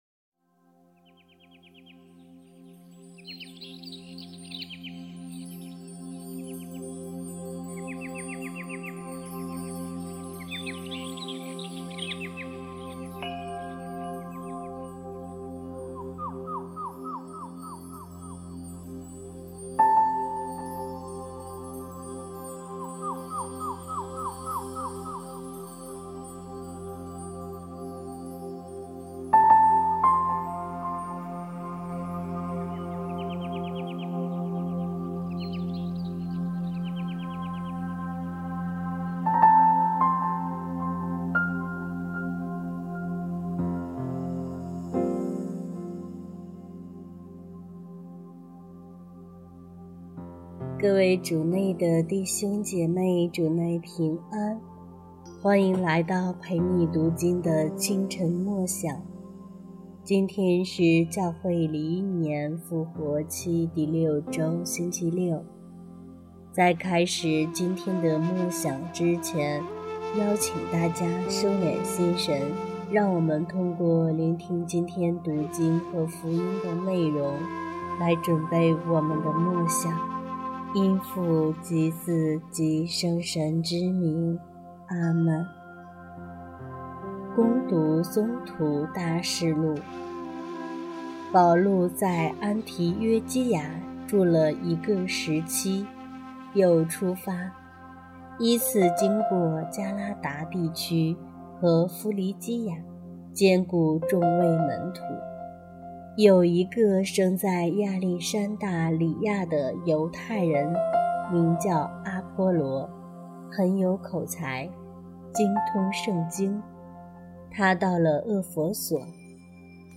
怎么最近又是催眠曲的调子，能不能像壹明头条的人一样啊！
这个每日反省，声音语速催眠！！